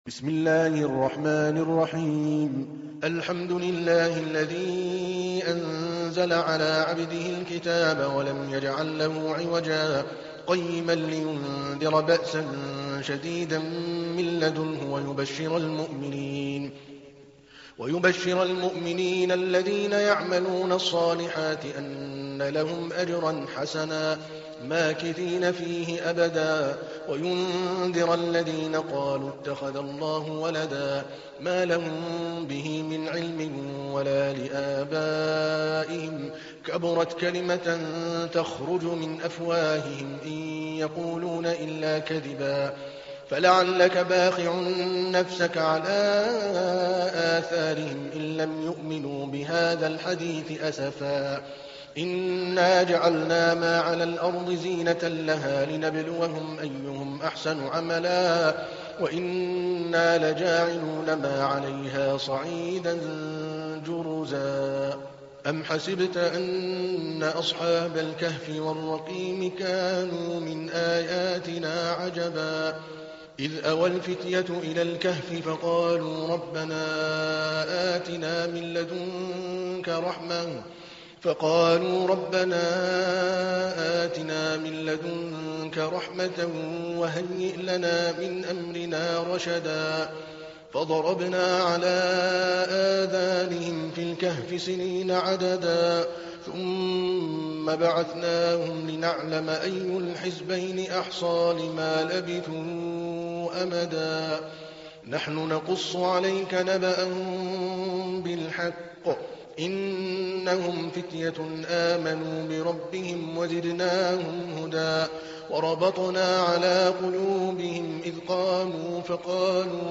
تحميل : 18. سورة الكهف / القارئ عادل الكلباني / القرآن الكريم / موقع يا حسين